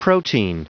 Prononciation du mot protein en anglais (fichier audio)
Prononciation du mot : protein